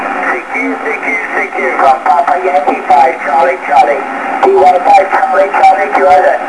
SSB